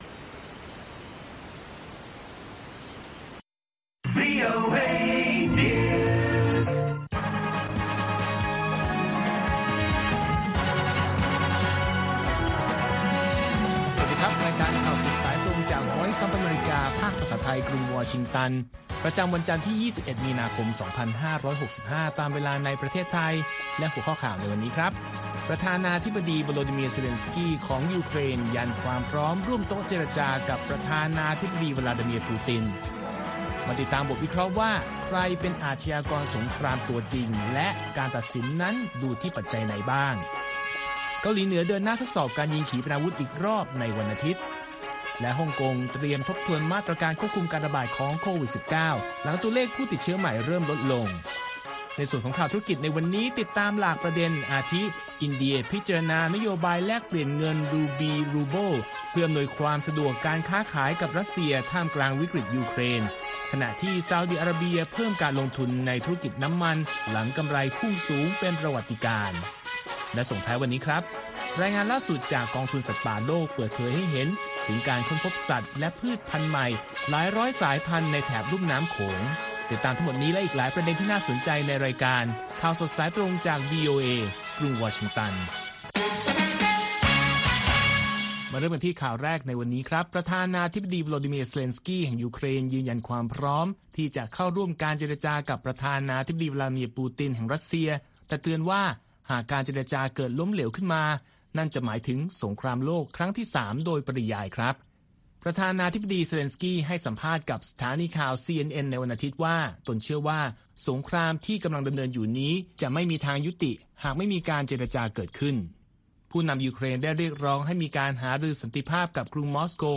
ข่าวสดสายตรงจากวีโอเอ ภาคภาษาไทย 8:30–9:00 น. ประจำวันจันทร์ที่ 21 มีนาคม 2565 ตามเวลาในประเทศไทย